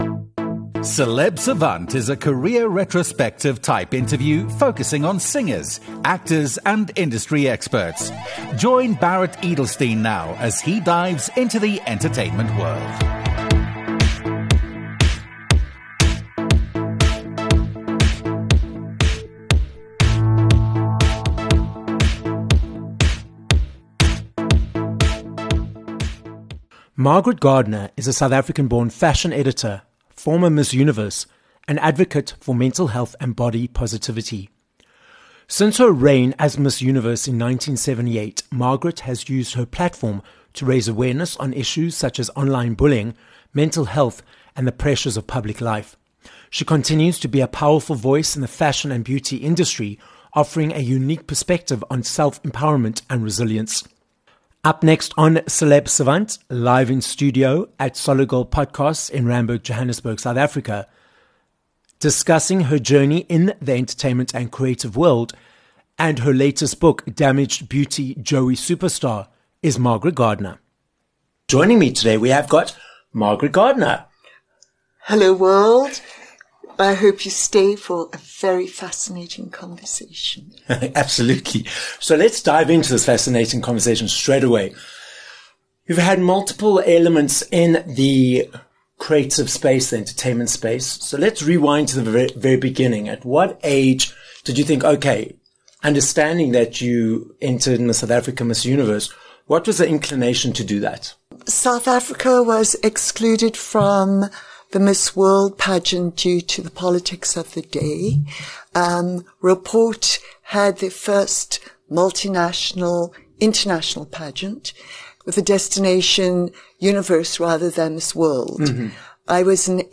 Margaret Gardiner - a South African-born, American-based model, television personality, journalist, and author - joins us live in studio on this episode of Celeb Savant. Margaret tells us about her multi-decade journey in the industry, which includes being the first South African to win Miss Universe in 1978, her work as a fashion editor for the Golden Globes in Los Angeles and her new book, Damaged Beauty: Joey Superstar. This episode of Celeb Savant was recorded live in studio at Solid Gold Podcasts, Johannesburg, South Africa.